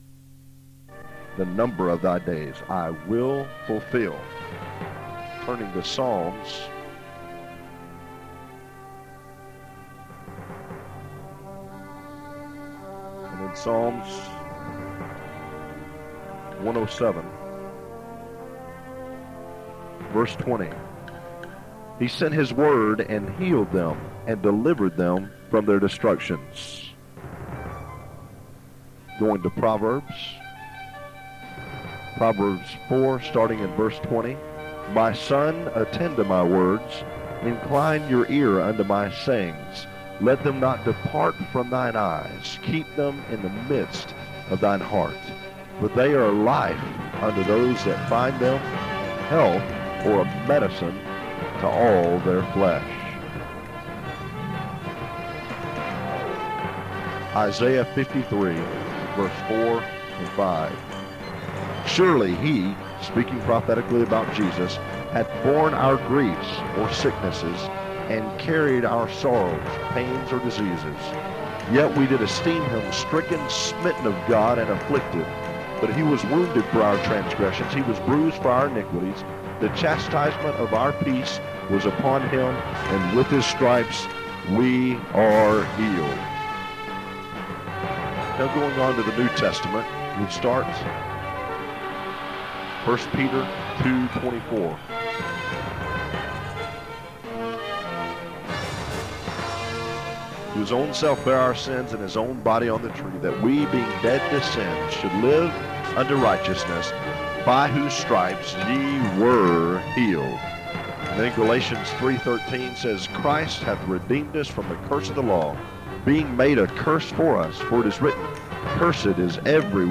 Healing Scriptures with music